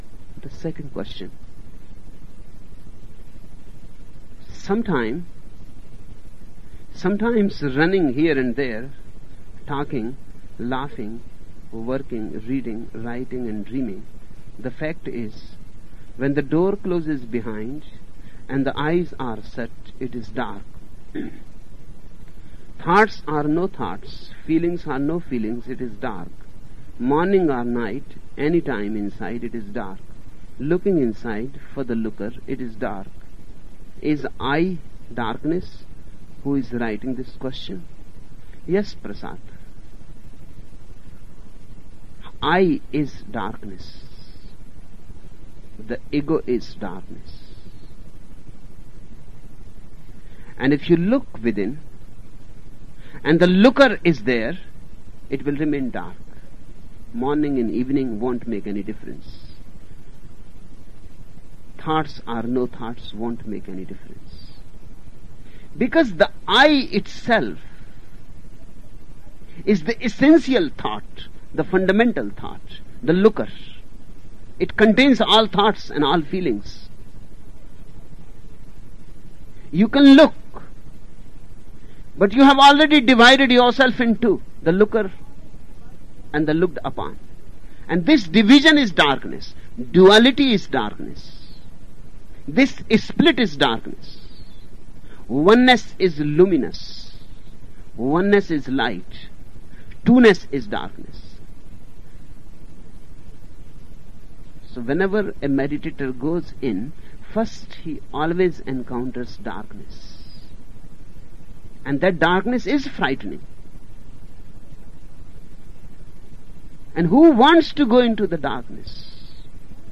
Each program has two parts, Listening Meditation (Osho discourse) and Satsang Meditation.
The Listening Meditations in the following programs include discourses given by Osho from 1974 – 1988 and were given in Pune, India; Rajneeeshpuram, OR; Kathmandu, Nepal; Punte del Este, Uruguay; Mumbai and Pune, India.